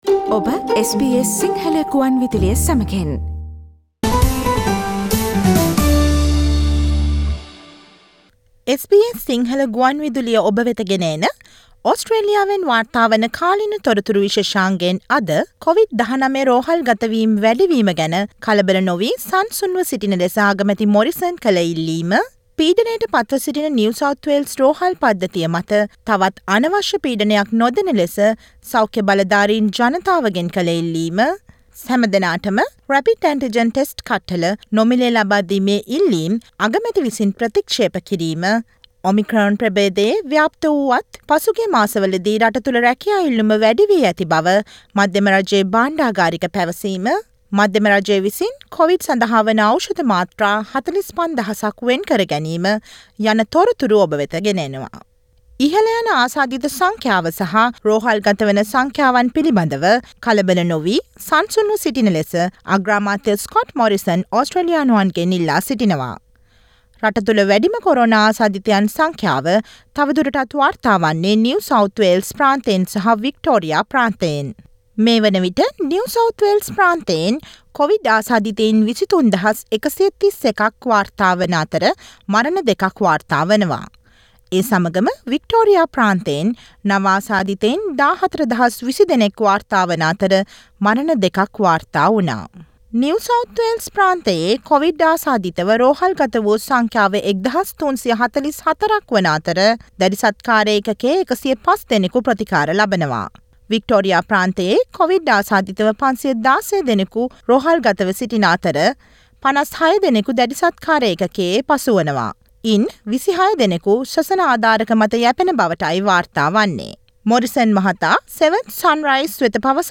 නිව් සවුත් වේල්ස් සහ වික්ටෝරියා රෝහල් පද්ධති මත කොවිඩ් නිසා ඇති වූ දැඩි පීඩනය, Omicron ව්‍යාප්ත වුණත් ඕස්ට්‍රේලියාව පුරා රැකියා ඉල්ලුම ඉහළ යාම සහ නවතම කොවිඩ් තොරතුරු රැගත් ජනවාරි 04 වෙනි අඟහරුවාදා ප්‍රචාරය වූ SBS සිංහල ගුවන් විදුලි සේවයේ කාලීන තොරතුරු ප්‍රචාරයට සවන් දෙන්න.